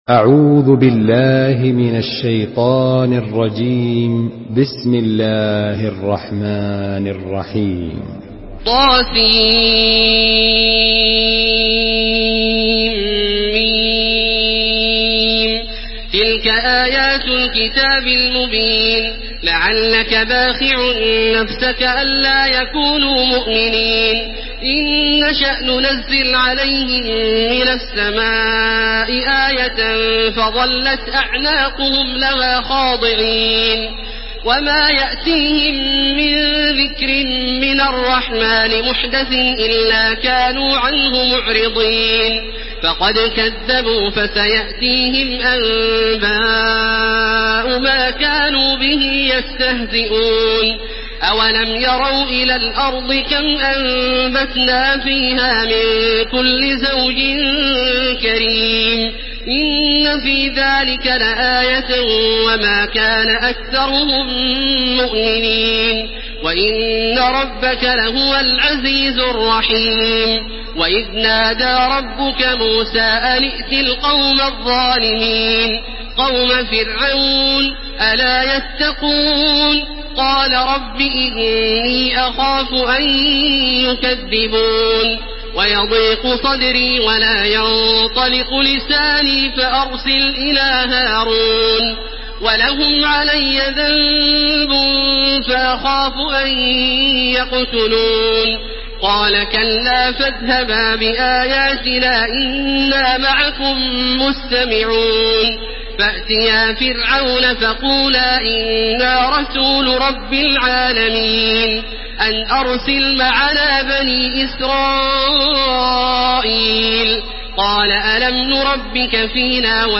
سورة الشعراء MP3 بصوت تراويح الحرم المكي 1431 برواية حفص
مرتل